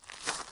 Rock Foot Step 1.wav